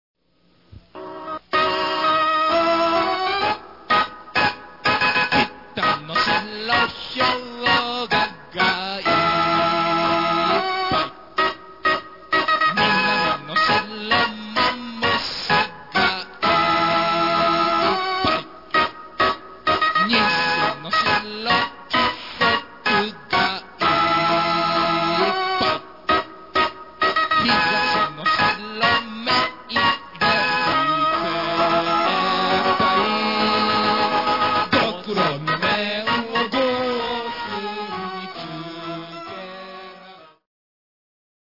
マスタリングにより、音が格段によくなりました。